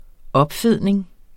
Udtale [ ˈʌbˌfeðˀneŋ ]